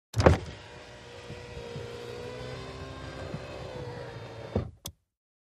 VEHICLES - AUTO ACCESSORIES: Electric window, open and close.